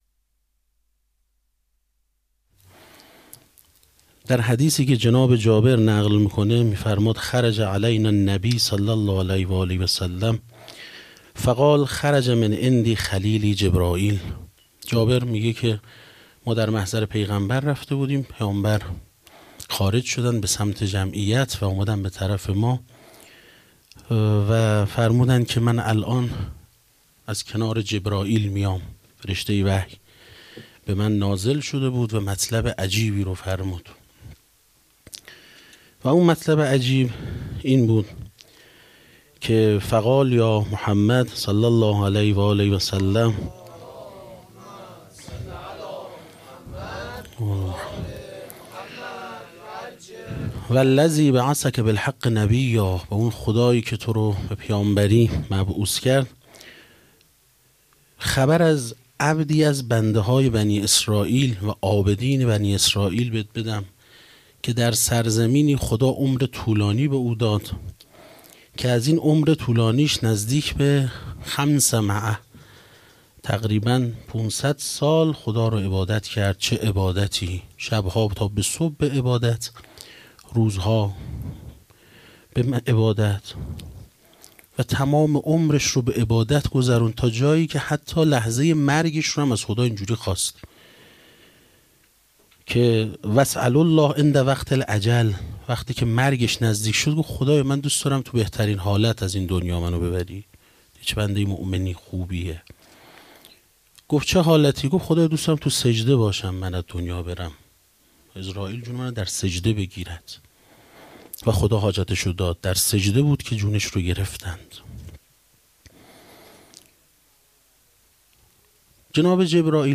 حسینیه بیت النبی - مراسم عزاداری فاطمیه اول
سخنرانی